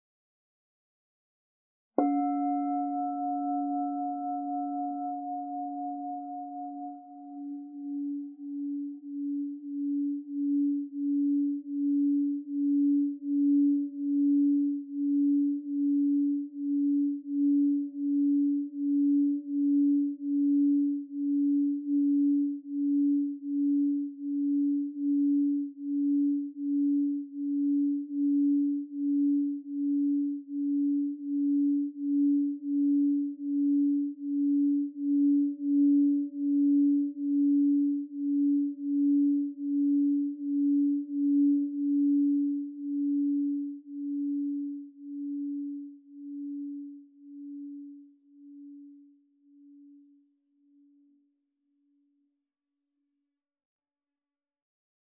Meinl Sonic Energy 6,75" Essence Solfeggio Crystal Singing Bowl 285 Hz, Türkisgrün (ESOLCSB285)
Feature: Meisterhaft handgefertigtFeature: Hergestellt aus hochreinem QuarzFeature: Extrem langanhaltende ResonanzFeature: Klare, kräftige und volle …